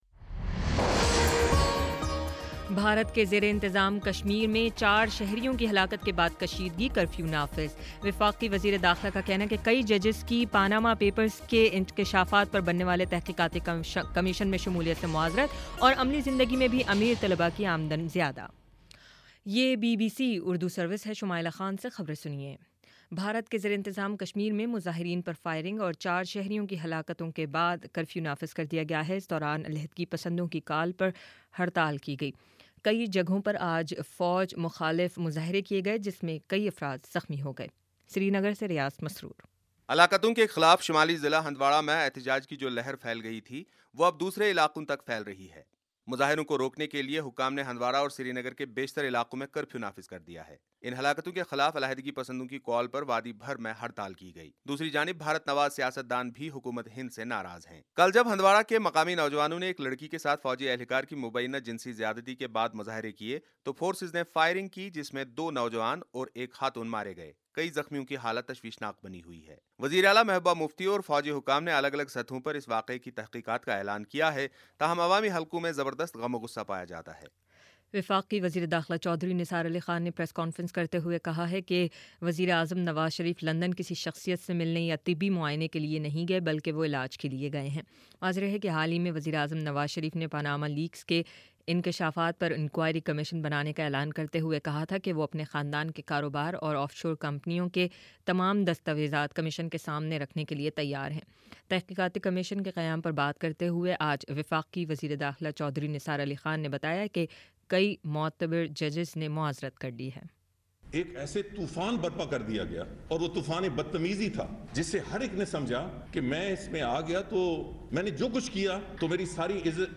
اپریل 13 : شام سات بجے کا نیوز بُلیٹن